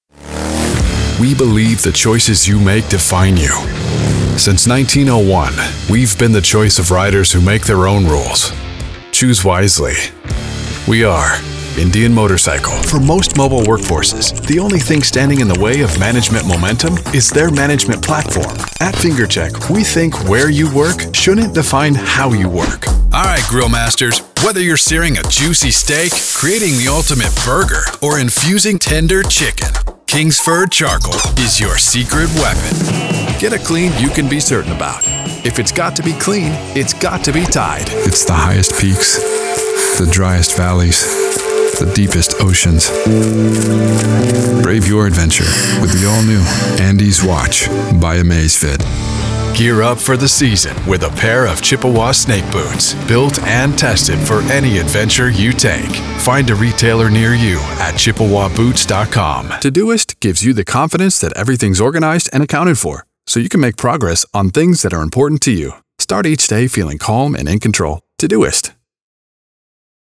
A deep, rich, gritty male voice talent for commercials and narration
Commercial Voiceover Demo
Commercial Voiceover Demo_if.mp3